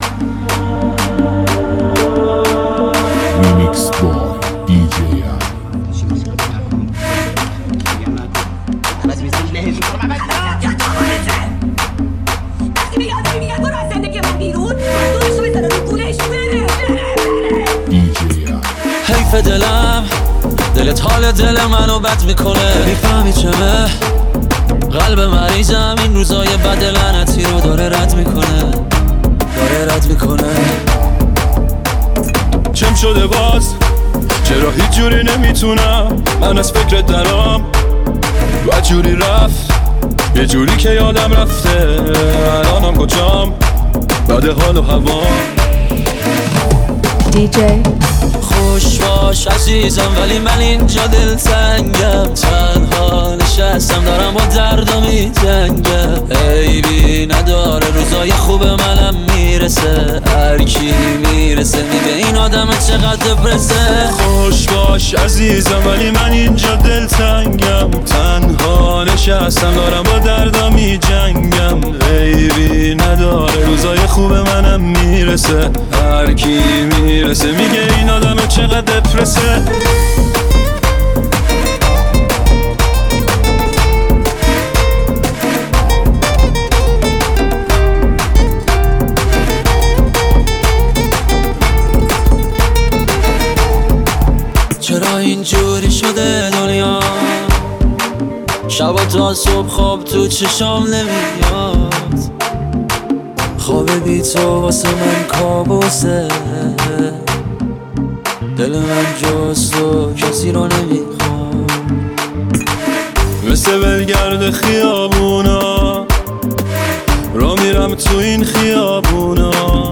لذت بردن از موسیقی پرانرژی و بیس دار، هم‌اکنون در سایت ما.